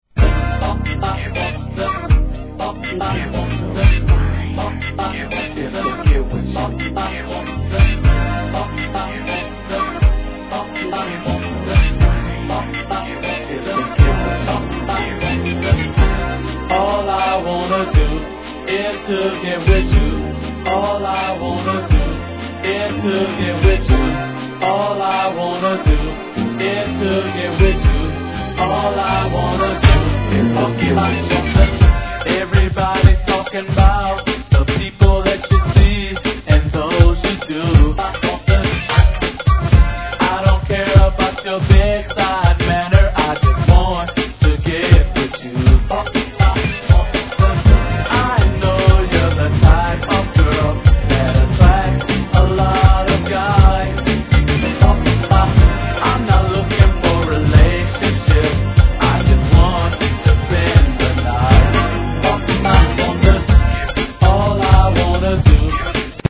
HIP HOP/R&B
NEW JACK SWINGファンにもお勧めできるR&B MIX収録!!